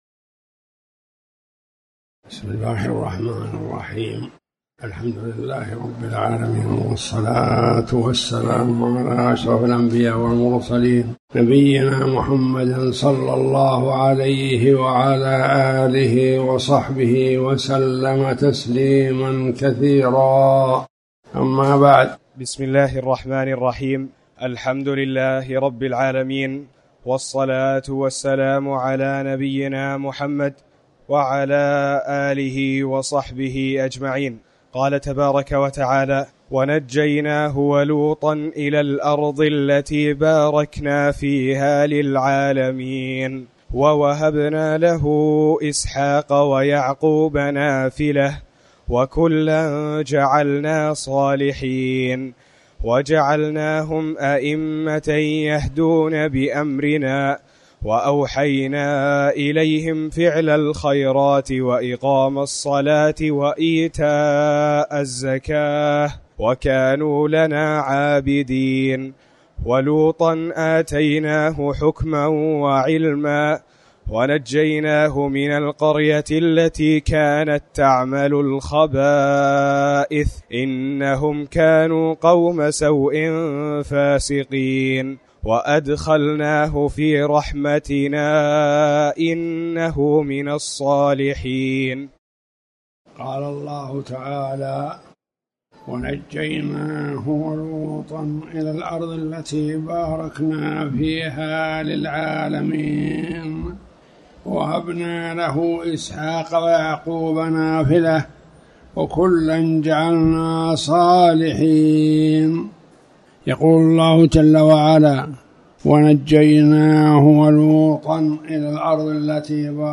تاريخ النشر ١٣ رجب ١٤٤٠ هـ المكان: المسجد الحرام الشيخ